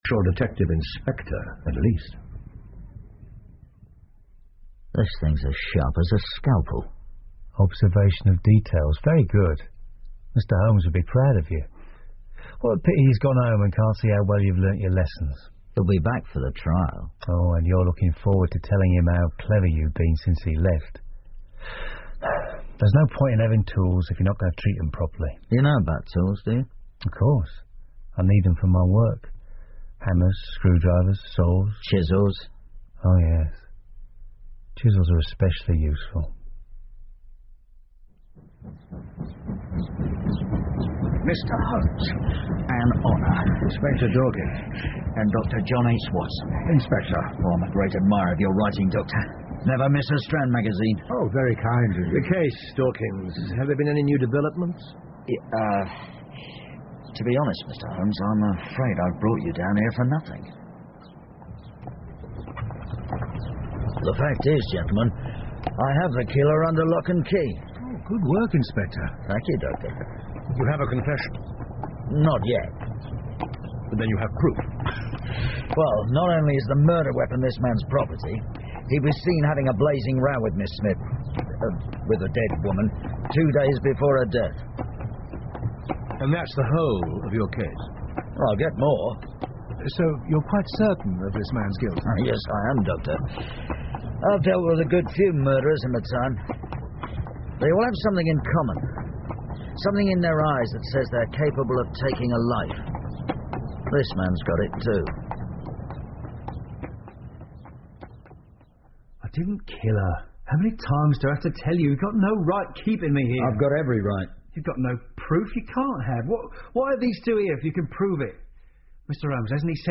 福尔摩斯广播剧 The Shameful Betrayal Of Miss Emily Smith 2 听力文件下载—在线英语听力室